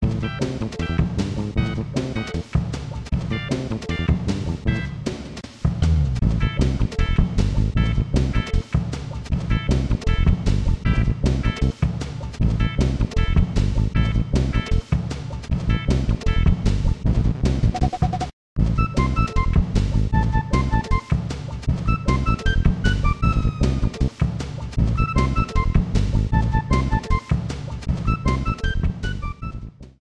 Key A minor
BPM 130
is less upbeat and more slow-paced